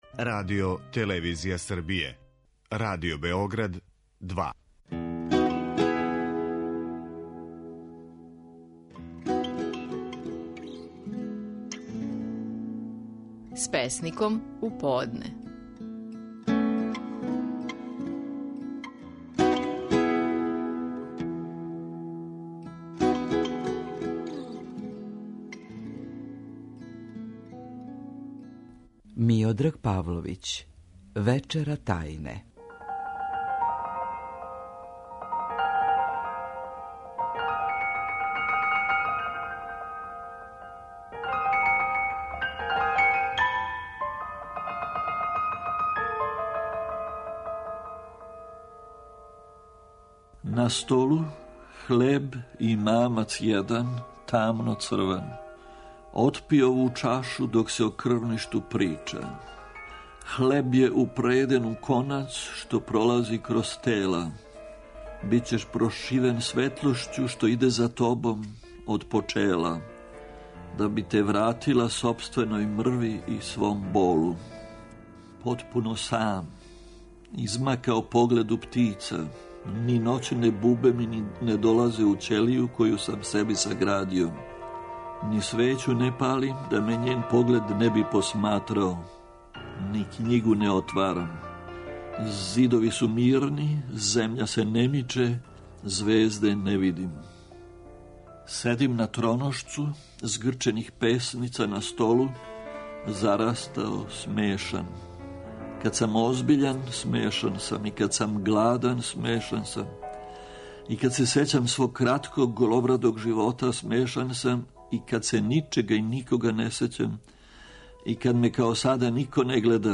Стихови наших најпознатијих песника, у интерпретацији аутора.
Миодраг Павловић говори своју песму „Вечера тајне".